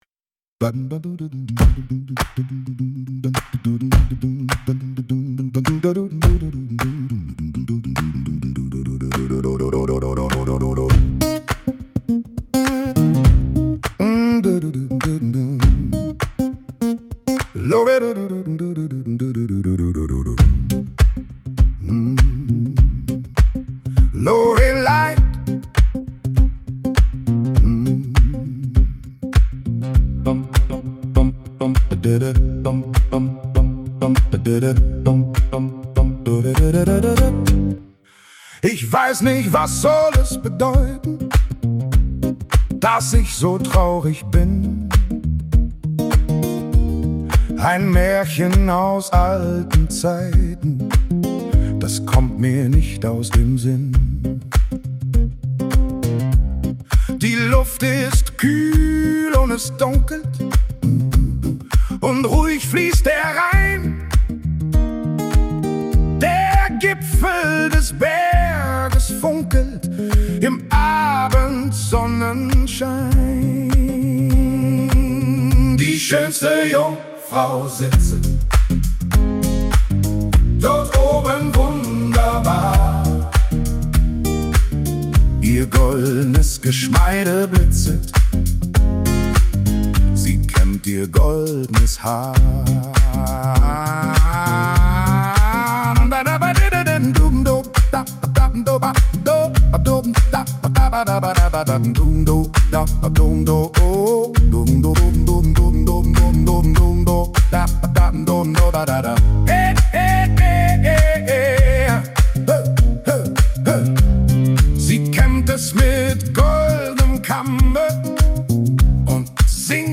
(Musikalische Inhalte wurden teilweise mit KI-Unterstützung generiert)
Unsere moderne Interpretation